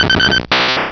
Cri de Taupiqueur dans Pokémon Rubis et Saphir.